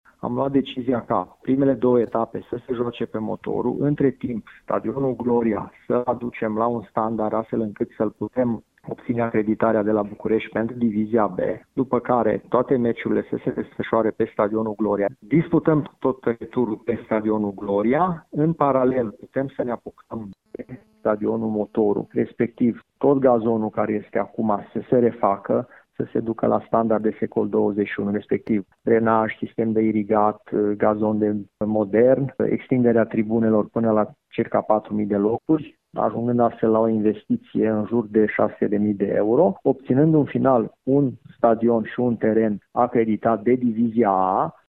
Viceprimarul Aradului, Călin Bibarţ, explică mersul lucrărilor din următoarea perioadă: